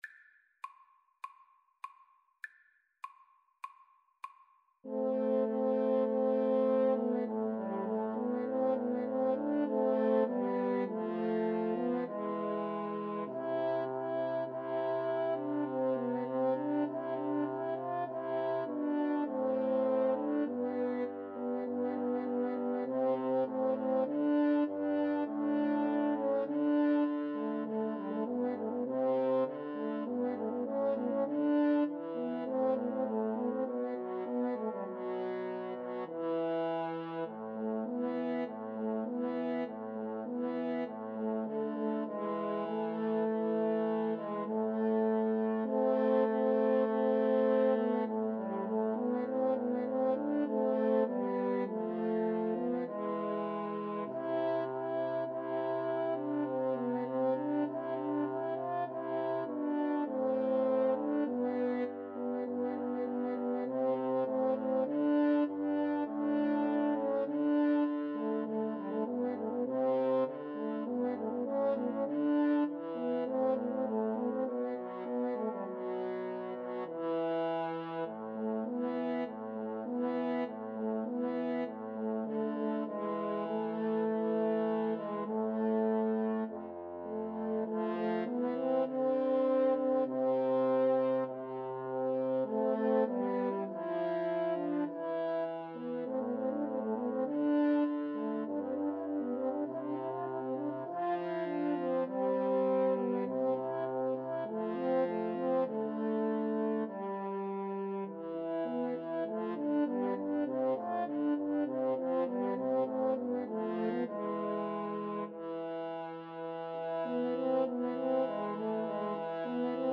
[Moderato]
Classical (View more Classical French Horn Trio Music)